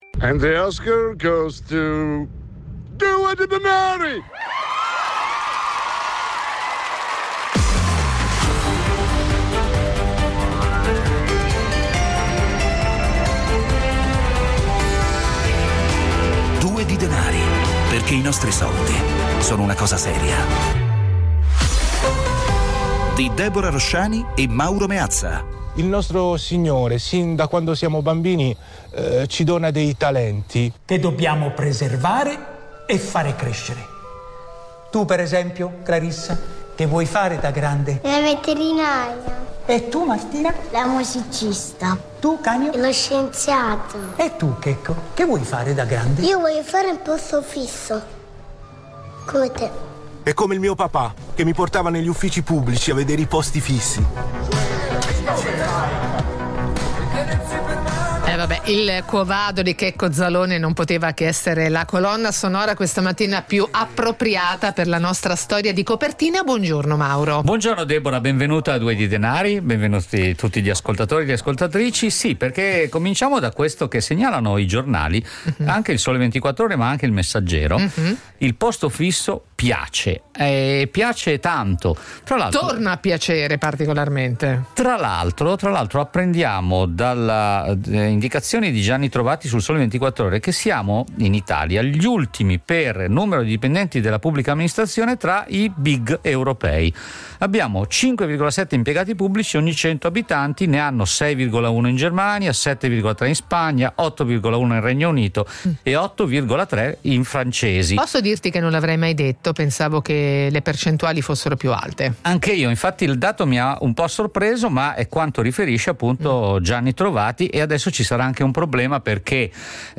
Ogni giorno, su Radio 24, in questo spazio vengono affrontati con l'aiuto degli ospiti più competenti, uno sguardo costante all'attualità e i microfoni aperti agli ascoltatori.
La cifra, da sempre, è quella dell’ "autorevoleggerezza" : un linguaggio chiaro e diretto, alla portata di tutti.